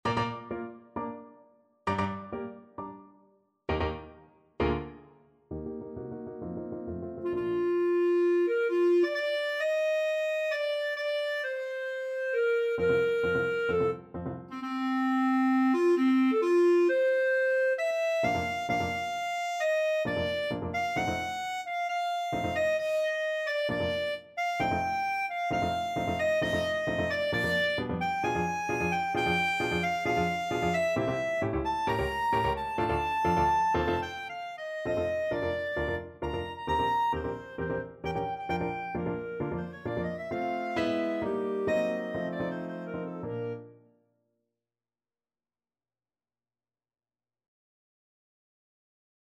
2/2 (View more 2/2 Music)
Classical (View more Classical Clarinet Music)